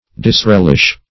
Disrelish \Dis*rel"ish\ (?; see Dis-), n.